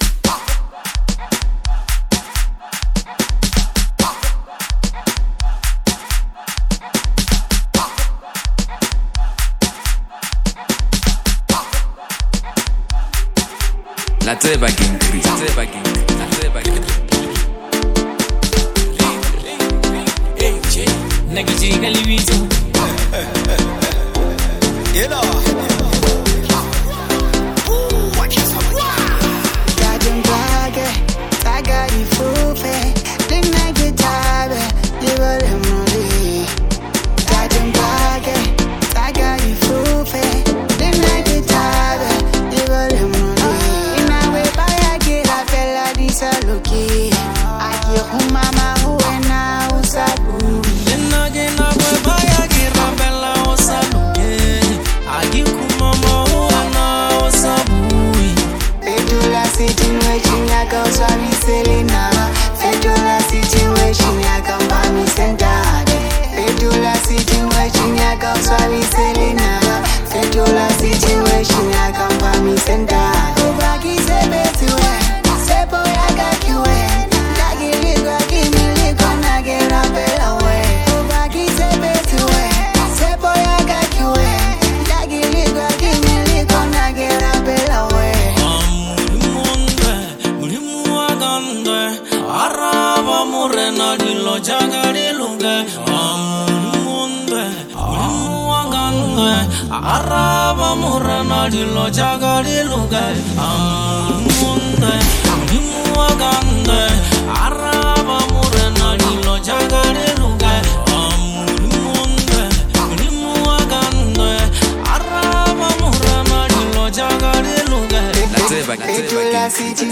Category: Lekompo